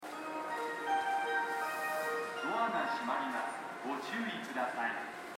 スピーカーはＴＯＡ型が設置されており音質も高音質です。
発車メロディーフルコーラスです。